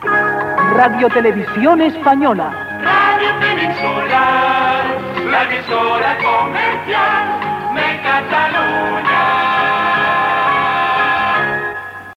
Identificació RTVE i indicatiu cantat de l'emissora